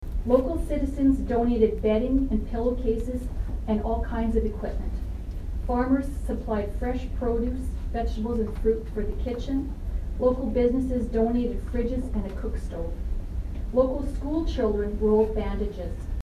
A celebration of PECMH’s centennial anniversary was held at the hospital Wednesday.